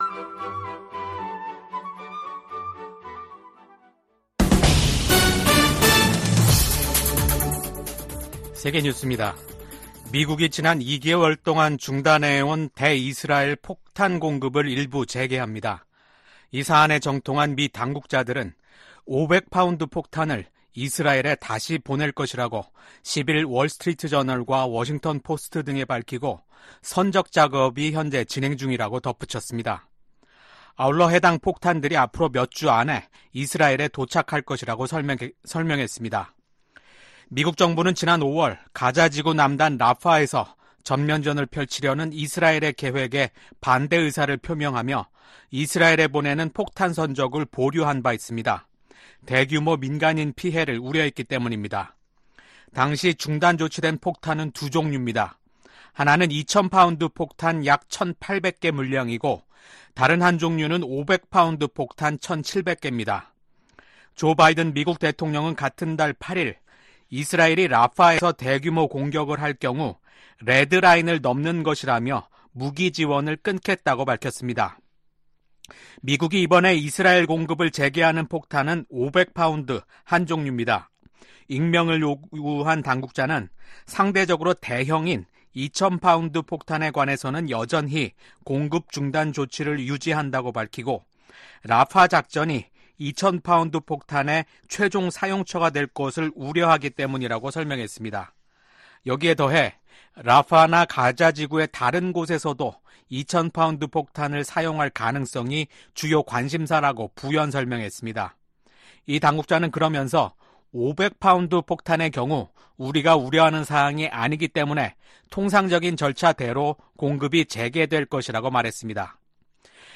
VOA 한국어 아침 뉴스 프로그램 '워싱턴 뉴스 광장' 2024년 7월 12일 방송입니다. 미국 대통령을 비롯한 북대서양조약기구(NATO∙나토) 정상들이 러시아에 대한 북한의 무기 수출을 규탄하는 공동선언을 발표했습니다. 미국 백악관은 한국과 일본 등 인도태평양 파트너 국가들이 참여하는 나토 정상회의에서 북러 협력 문제를 논의할 것이라고 예고했습니다. 한국과 나토 국가들 간 방산협력 등 연계가 앞으로 더욱 강화될 것이라고 미국 전문가들이 전망했습니다.